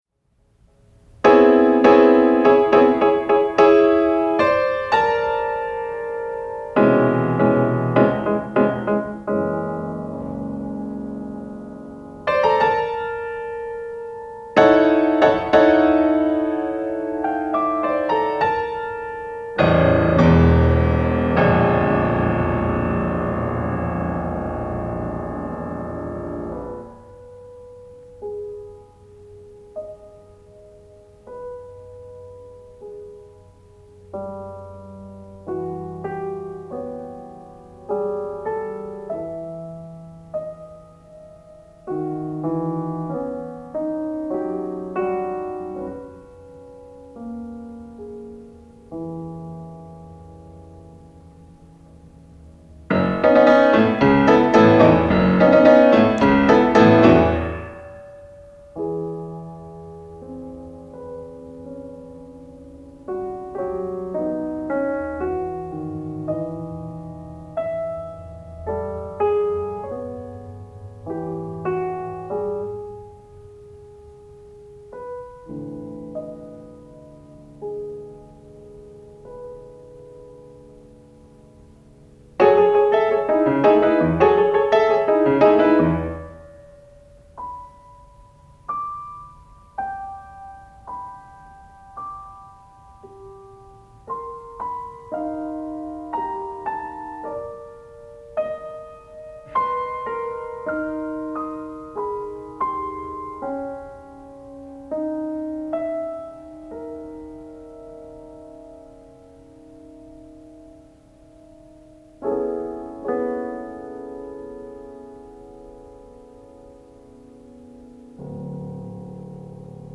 I knew in my soul that this woman would be my partner, my equal, my lover, my wife - I just had to convince her of that destiny:-) Being a composer, I did what I knew best and wrote - a music of travel from city to suburbs and back, a music of yearning and hope.
solo piano
This is contemporary music and may not be everyone's cuppa tea - I only ask that any who partake of its particular disturbance of air pressure listen through to the end, just like my then future wife did so graciously back in 1987. Coming Home...going home (Longing in Silent Apprehension)